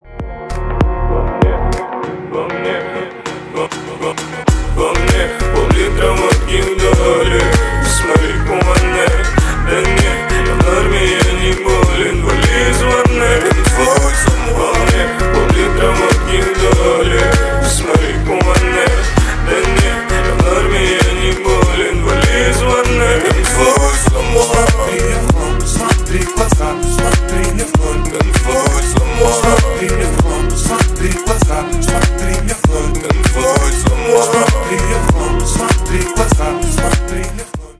Ремикс
грустные